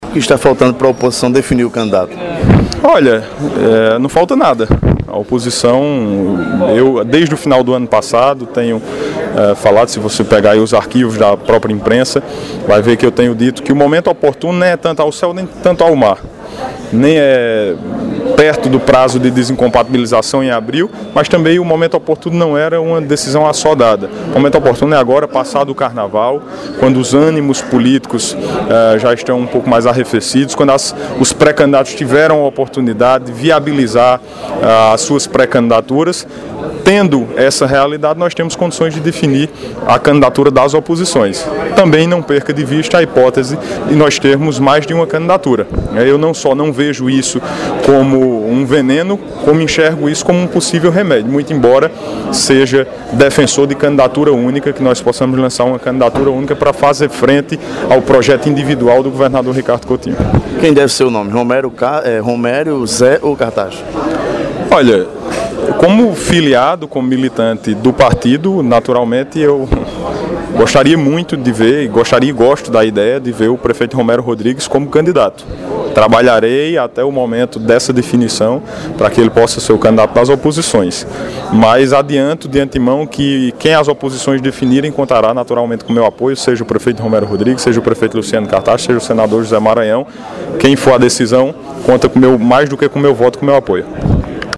O deputado estadual Bruno Cunha Lima (PSDB), defendeu durante entrevista ao PBVale, que as oposições na Paraíba devem seguir com candidatura única, na disputa contra o candidato apresentado pelo governador Ricardo Coutinho (PSB).
As declarações foram dadas durante a abertura dos trabalhos legislativos na sede do Ministério Público, ontem, em João Pessoa.